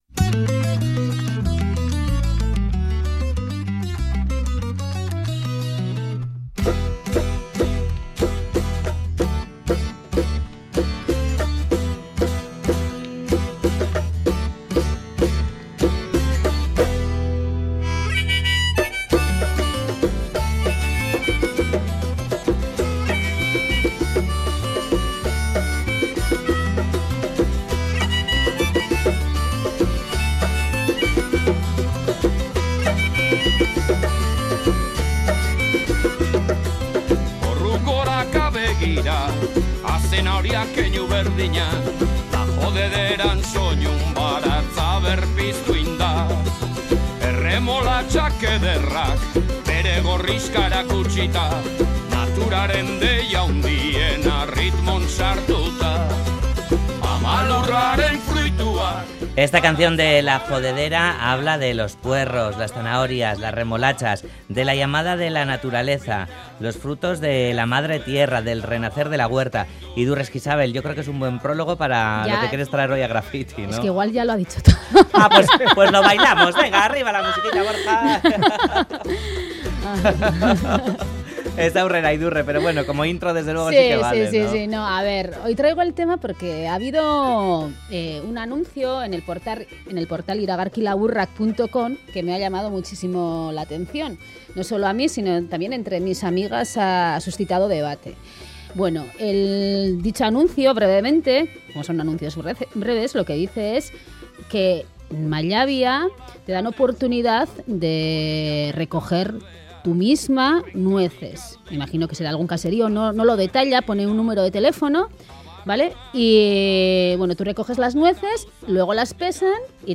Radio Euskadi GRAFFITI Volver a la huerta, algo habitual hoy en día. Última actualización: 27/09/2017 18:35 (UTC+2) La docente y antropóloga nos habla del boom que se vive por la horticultura.